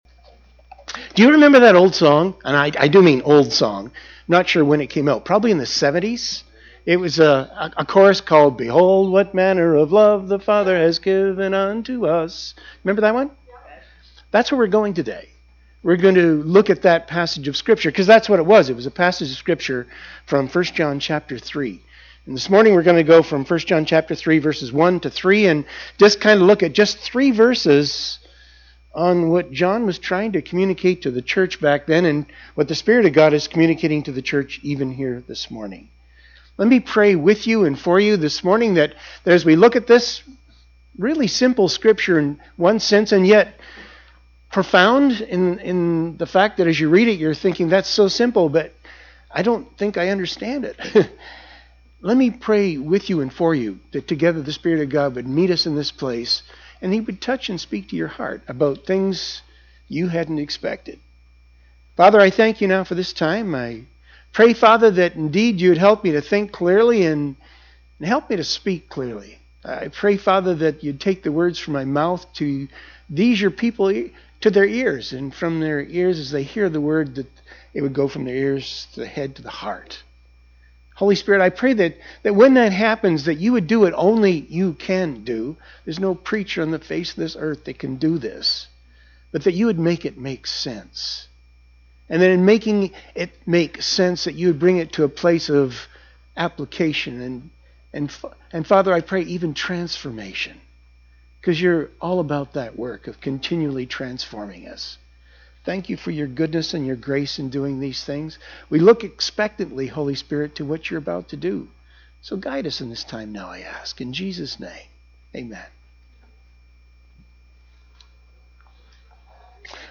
Behold What Manner of Love | Sermons | Resources